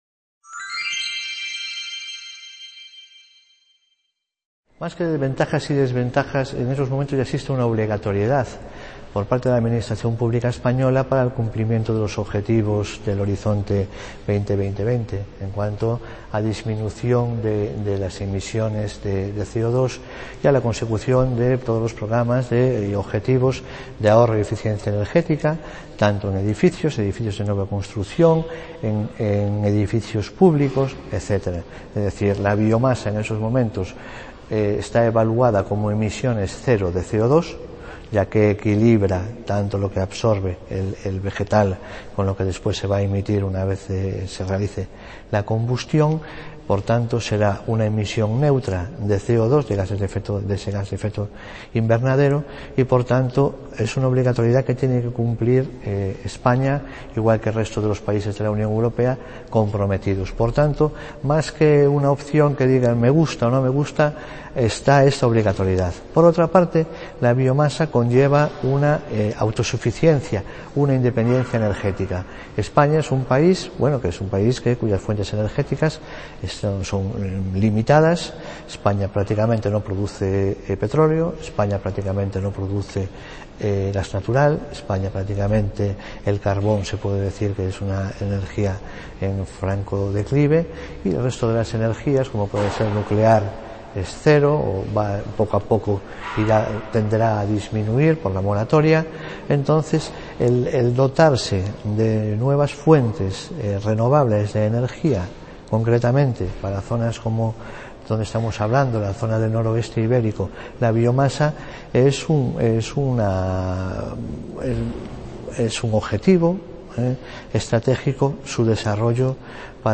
Experiencias en la utilización de Biomasa por la Diputación de Pontevedra, Entrevista
C.A. Ponferrada - II Congreso Territorial del Noroeste Ibérico